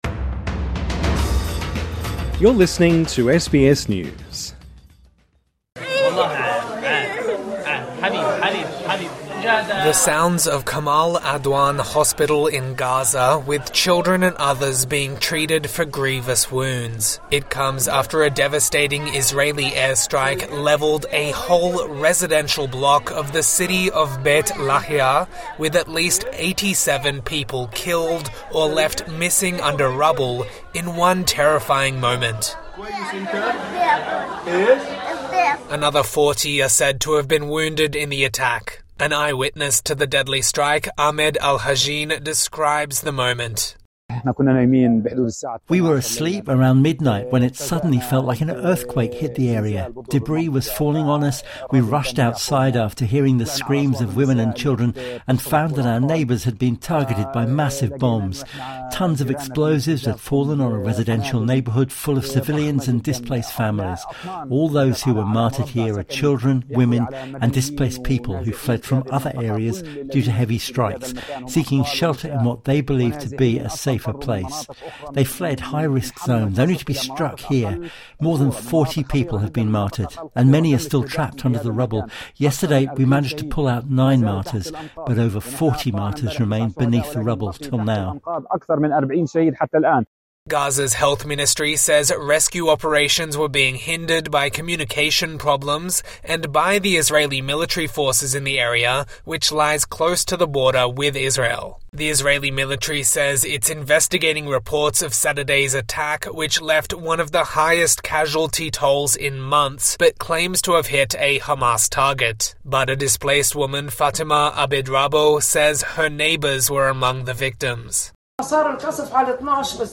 The sounds of Kamal Adwan Hospital in Gaza with children and others being treated for grievous wounds.